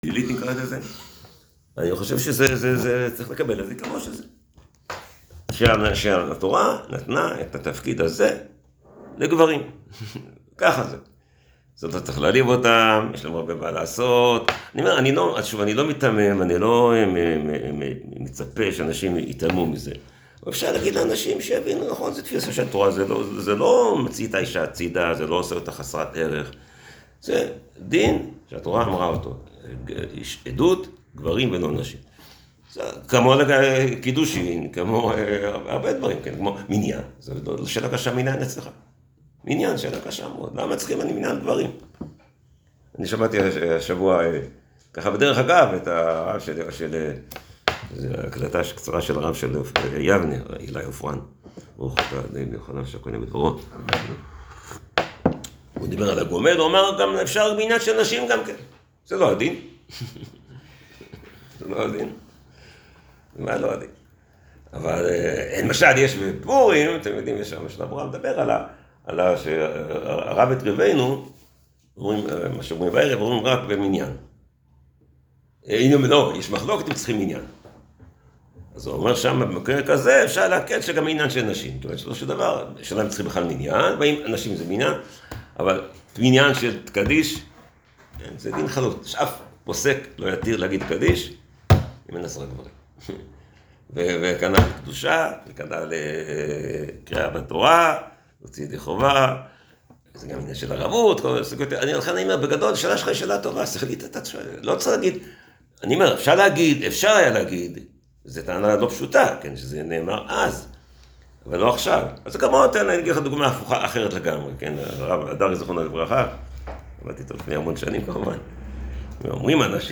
שיעור מעמד האישה